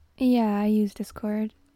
Download Discord sound effect for free.